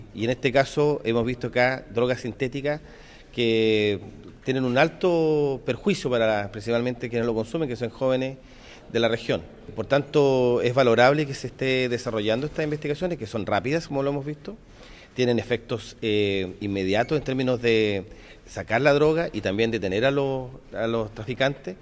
Por su parte, el delegado presidencial regional de Los Ríos, Jorge Alvial, aseguró que las drogas encontradas generalmente son consumidas por jóvenes y valoró la detención de las personas que portan estos narcóticos.